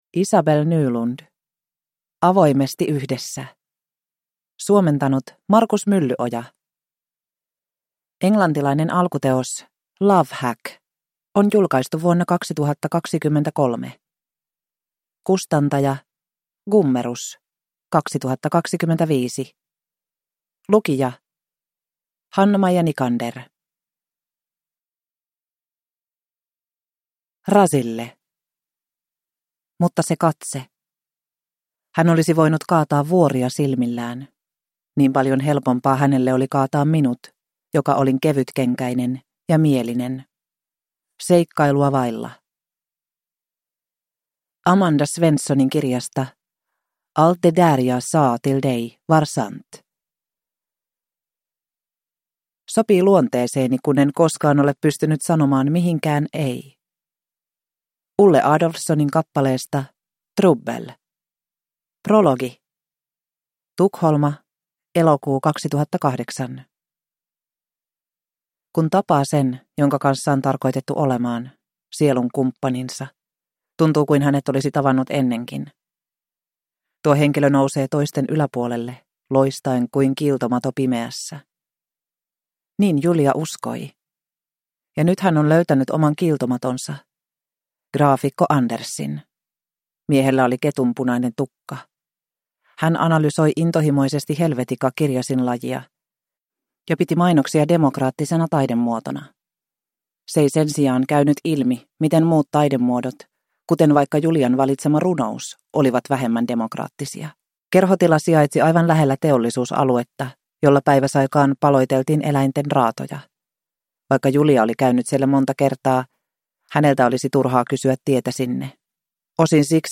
Avoimesti yhdessä – Ljudbok